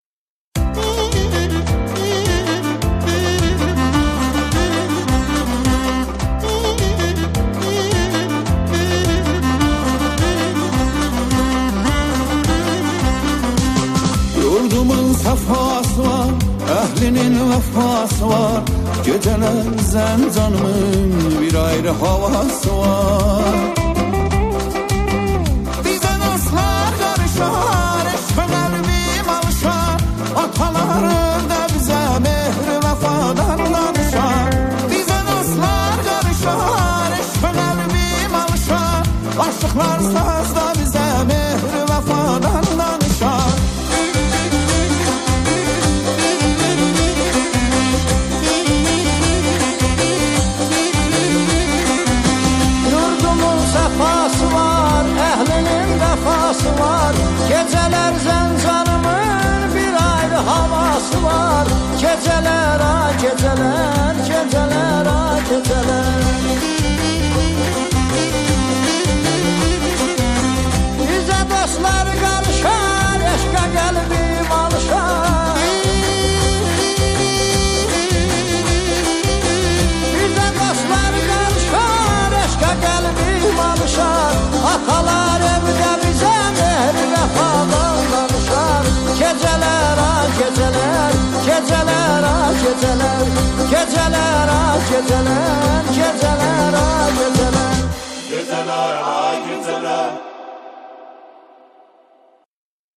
سرودهای شهرها و استانها
همخوانی شعری درباره "شهر زنجان"